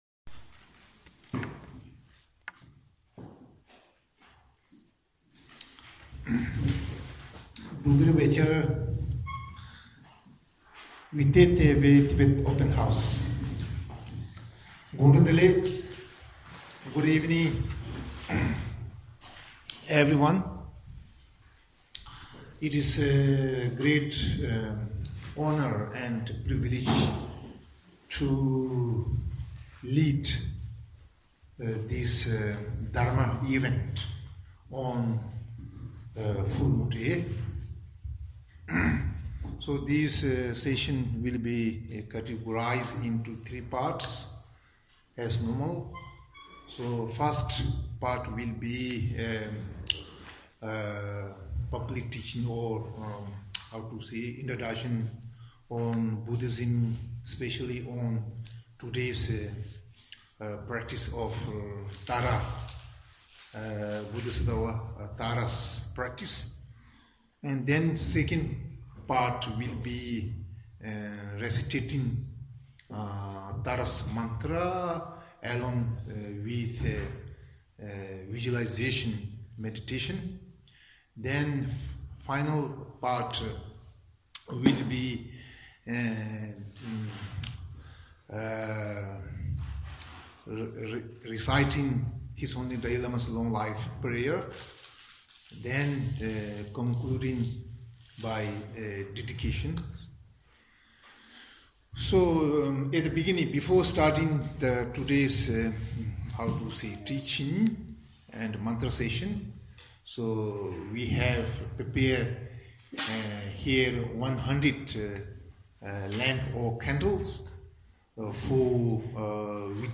V první jarní den, o úplňku 21. března 2019, jsme se pošesté sešli při společné recitaci manter za dlouhý život Jeho Svatosti dalajlámy v rámci iniciativy Milion manter pro dalajlámu.
mantrovanibrezen.mp3